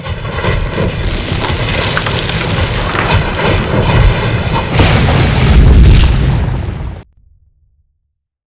cave.ogg